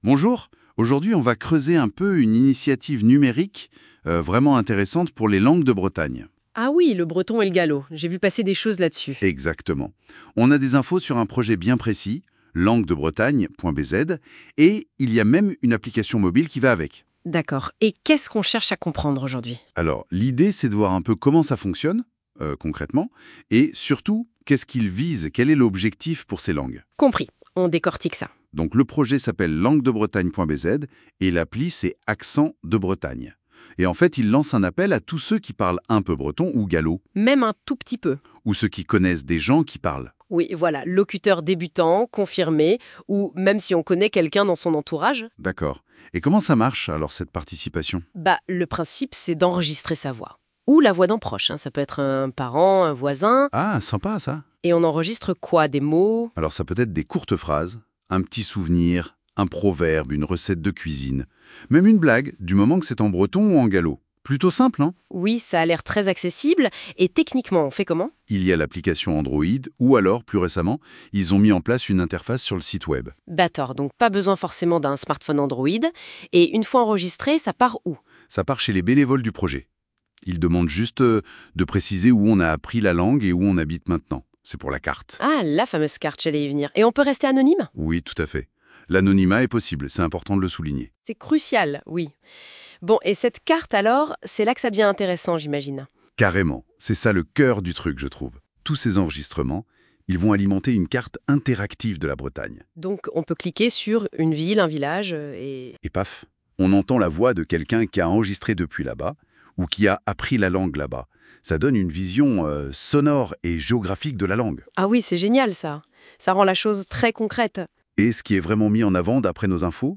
L’IA n’en finit pas de nous étonner… Voici un pseudo reportage à propos de notre projet et de l’application Langues de Bretagne créé par NoteBookLM.